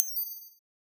Scene Transition.wav